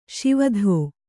♪ Śiva dhō